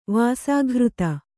♪ vāsāghřta